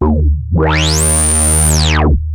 OSCAR  9 D#2.wav